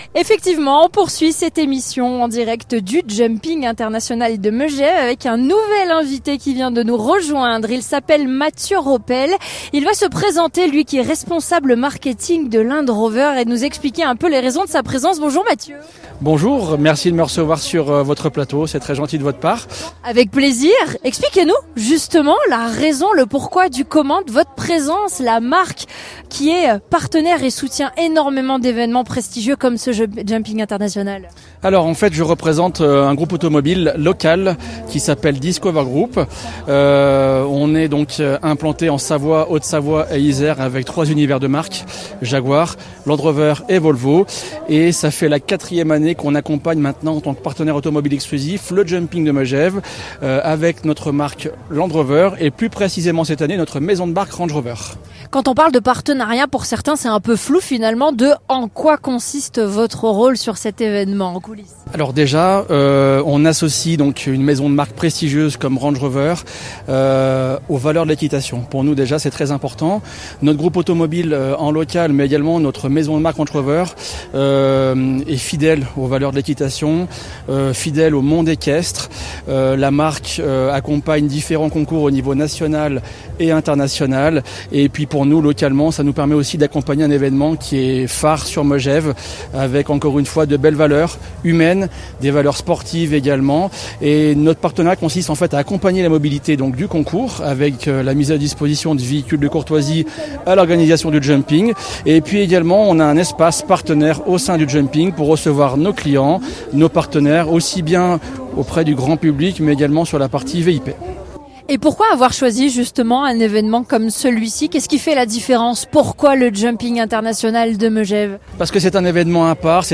Partenaire de l’événement, nous étions en émission spéciale en direct, pour faire vivre aux auditeurs toute l’ambiance de cette grande fête du sport et de l’élégance.
Interview